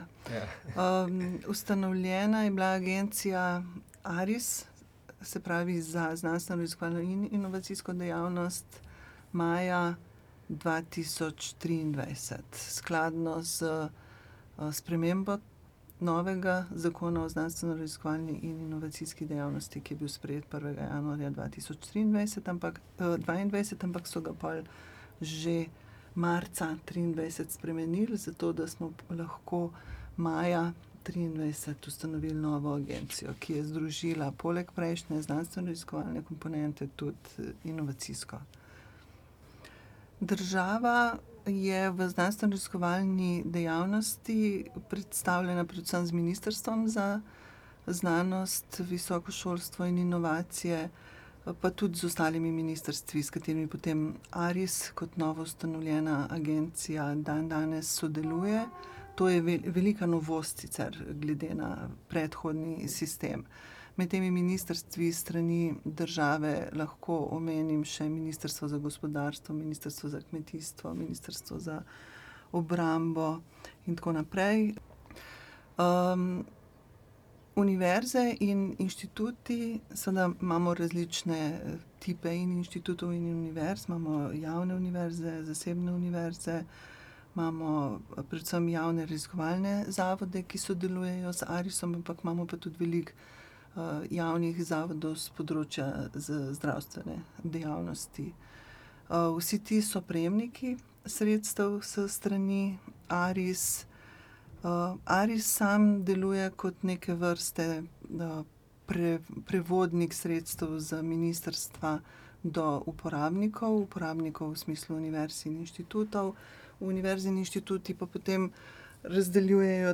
Doktorica Špela Stres predstavi, kako je bil ustanovljen ARIS in kakšno vlogo ima v slovenski znanosti.